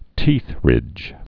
(tēthrĭj)